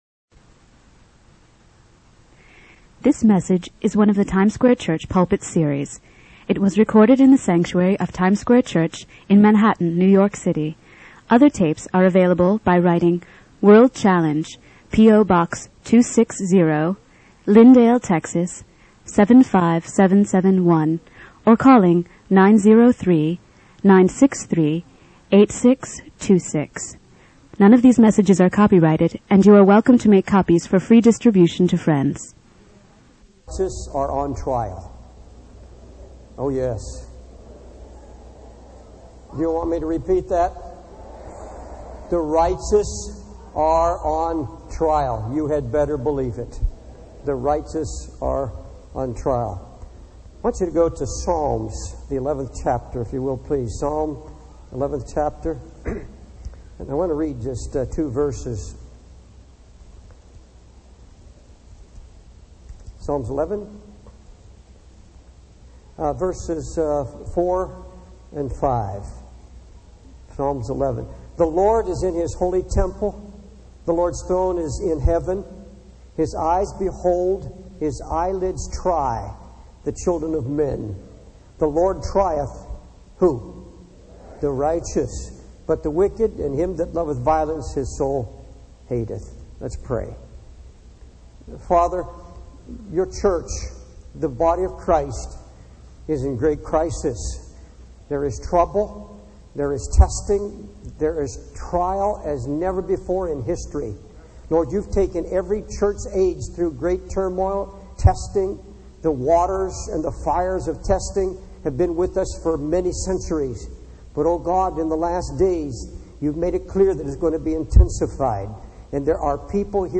In this sermon, the preacher reflects on his past messages about faith and acknowledges that he has not fully practiced what he preached.